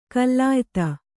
♪ kallāyta